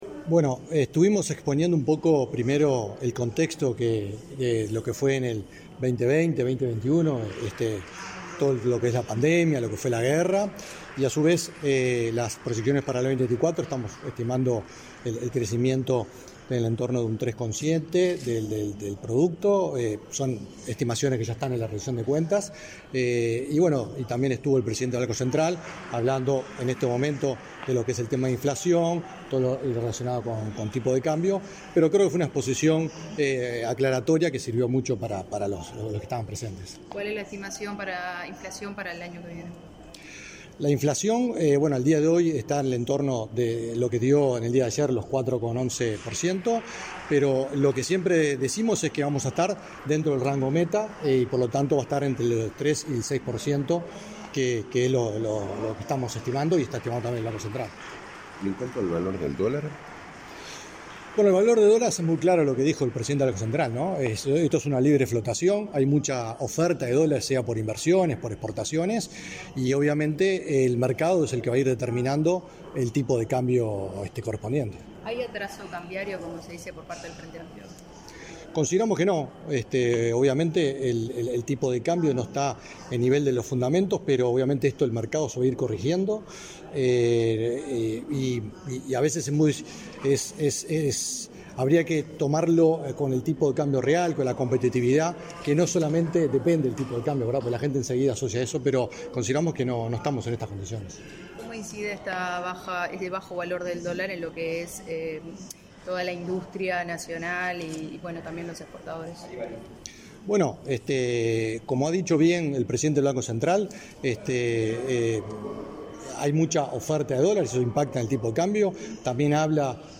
Declaraciones a la prensa del subsecretario del MEF, Alejandro Irastorza
Declaraciones a la prensa del subsecretario del MEF, Alejandro Irastorza 07/09/2023 Compartir Facebook X Copiar enlace WhatsApp LinkedIn Tras participar en la disertación sobre las perspectivas económicas para 2024, este 6 de setiembre, el subsecretario del Ministerio de Economía y Finanzas (MEF), Alejandro Irastorza, realizó declaraciones a la prensa.